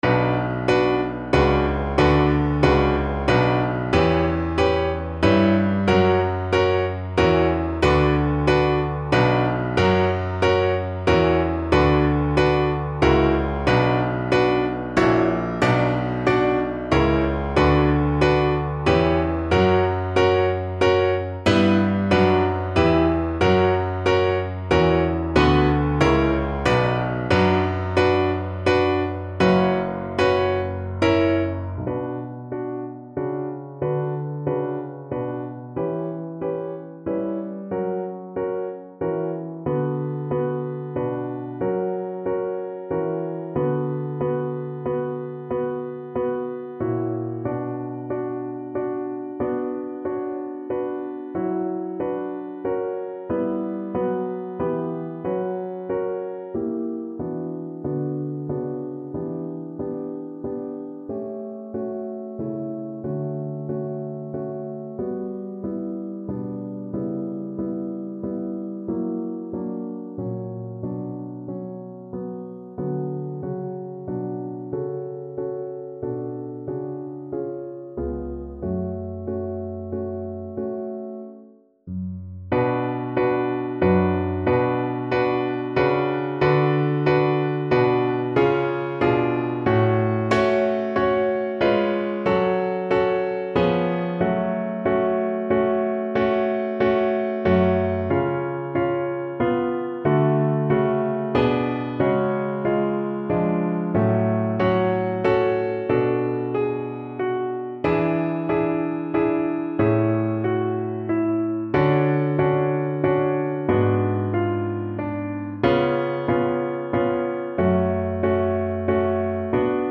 Classical Durante, Francesco Vergin, tutto amor - Virgin, fount of love Preghiera (Prayer) Cello version
Play (or use space bar on your keyboard) Pause Music Playalong - Piano Accompaniment Playalong Band Accompaniment not yet available transpose reset tempo print settings full screen
Cello
B minor (Sounding Pitch) (View more B minor Music for Cello )
12/8 (View more 12/8 Music)
Largo religioso . = 40
Classical (View more Classical Cello Music)